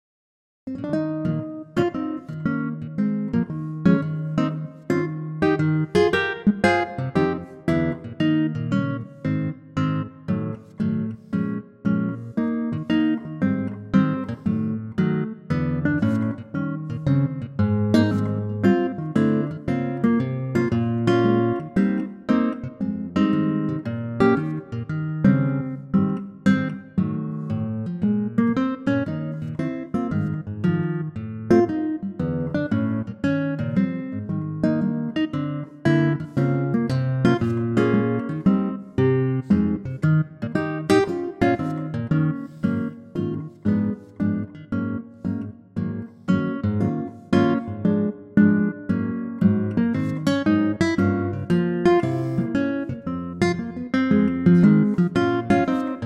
Unique Backing Tracks
key - Ab - vocal range - B to Eb
Superb acoustic guitar arrangement